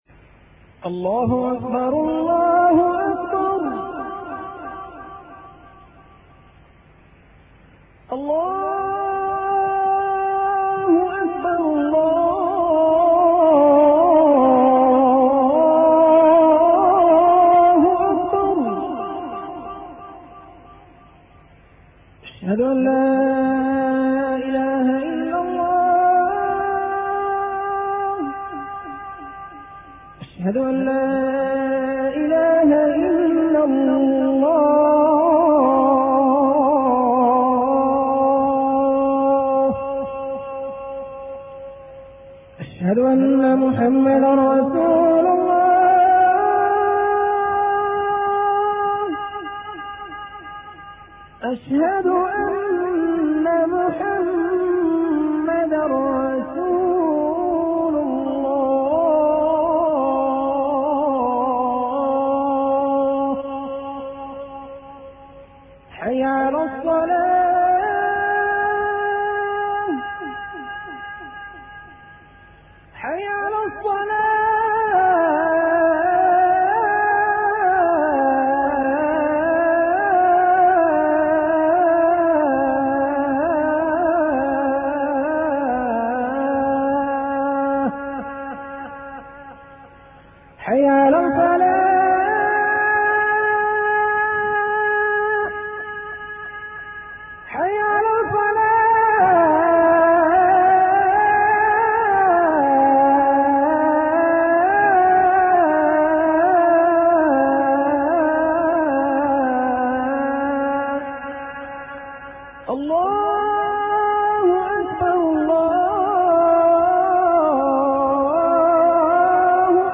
أناشيد ونغمات
عنوان المادة أذان-9